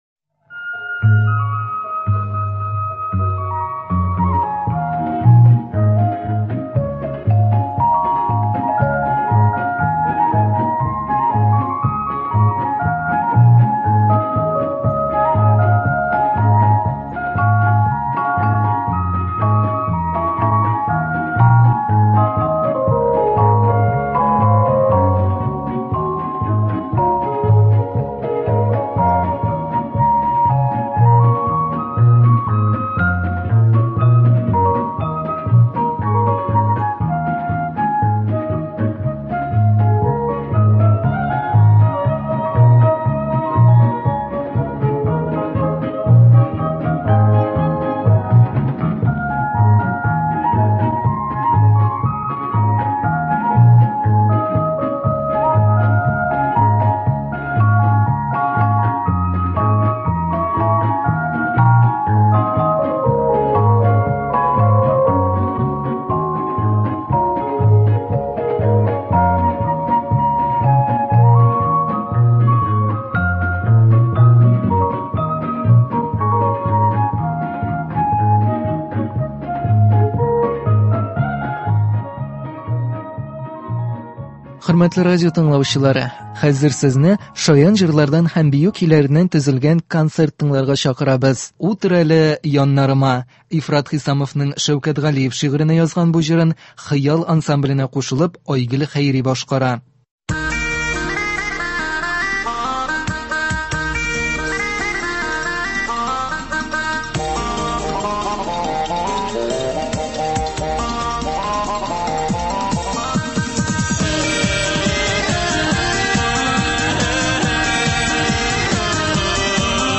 Шаян җырлар һәм бию көйләре.